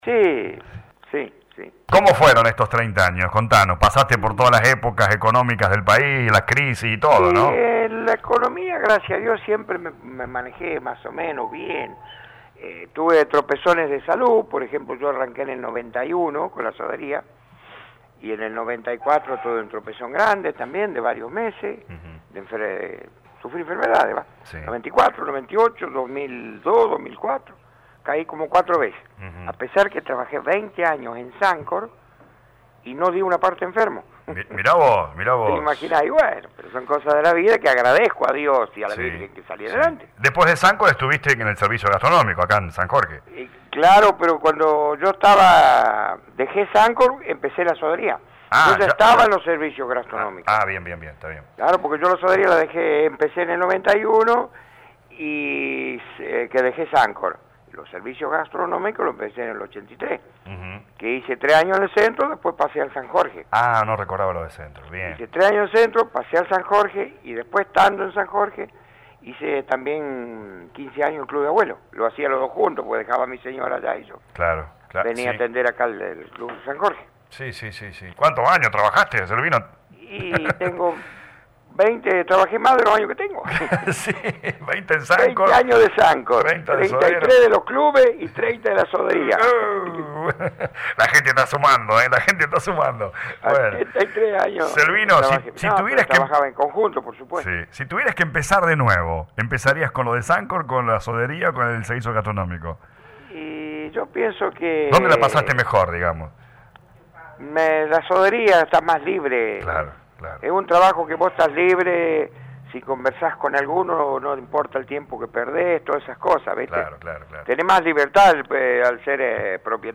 en diálogo con LA RADIO 102.9.